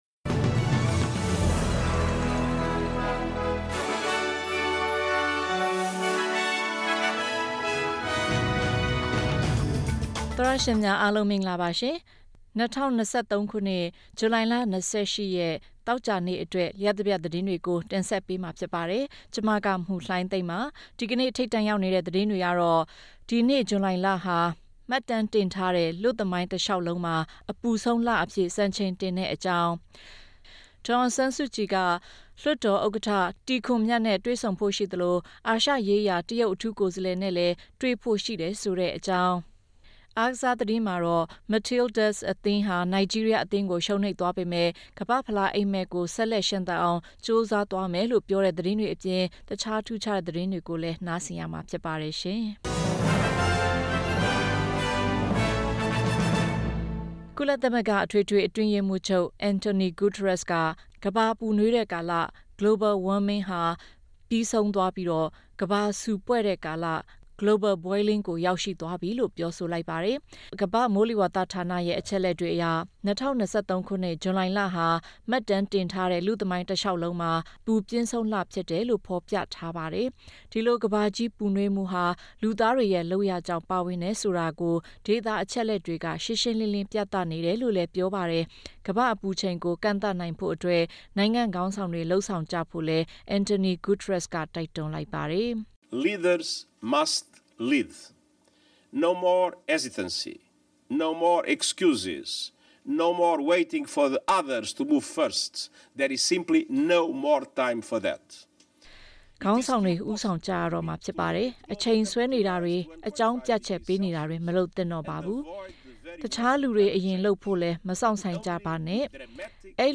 ဇူလိုင်လ ၂၈ ရက်အတွက် လျတ်တပြတ် သတင်းများ
SBS Audio Burmese News Flash